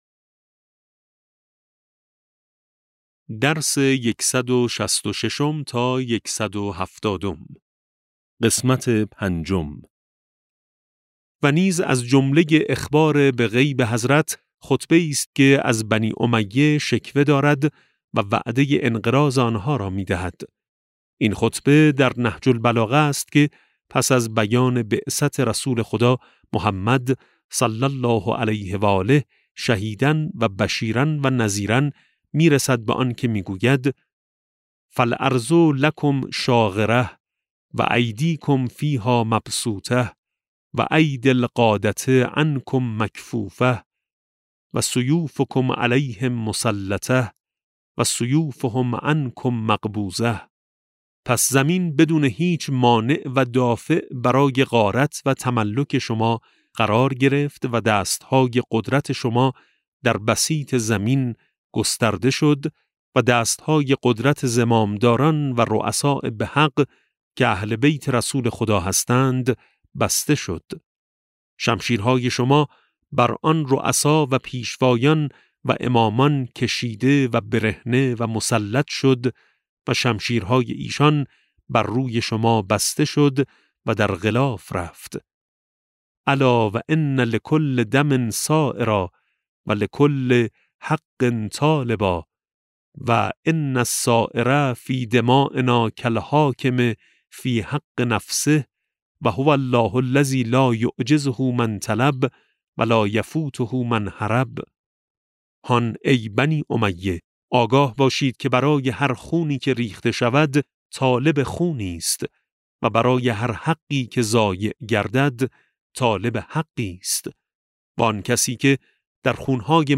کتاب صوتی امام شناسی ج12 - جلسه5